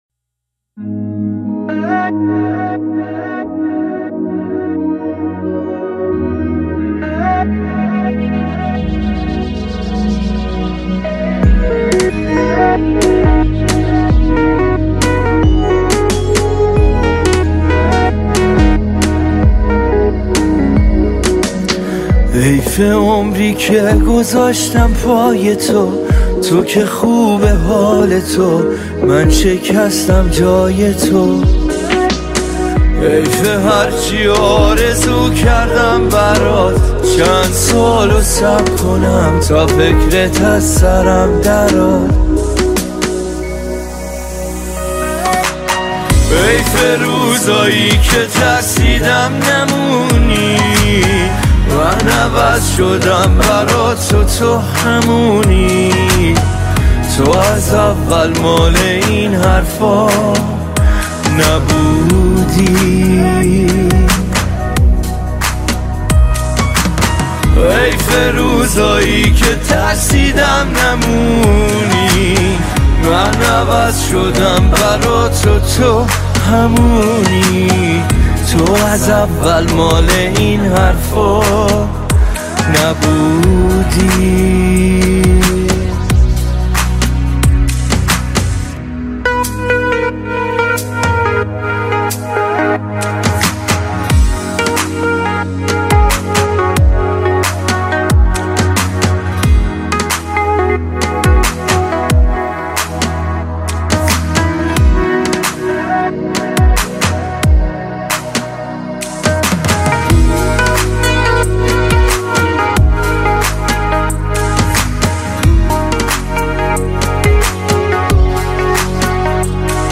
Категория: Иранские